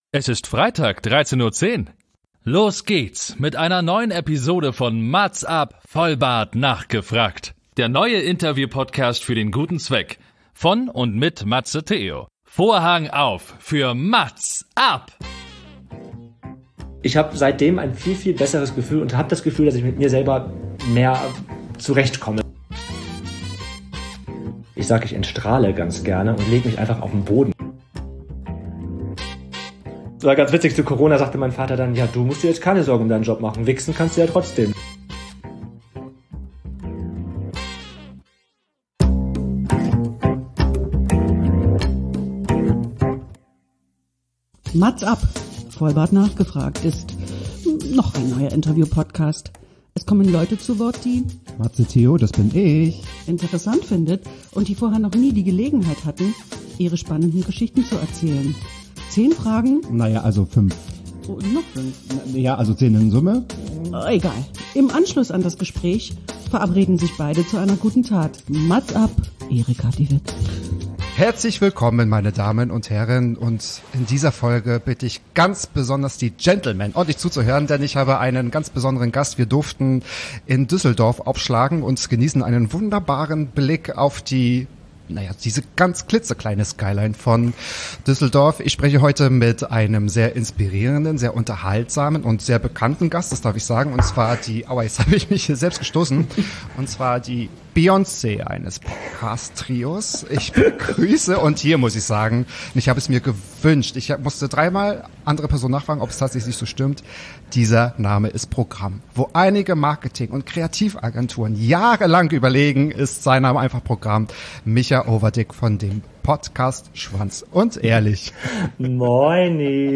MAZZ AB - der Interview-Podcast mit den einzigartigen Fragen, der jeden 13. Tag des Monats erscheint. Hier stelle ich ungewöhnliche Fragen an faszinierende Persönlichkeiten aus verschiedenen Bereichen.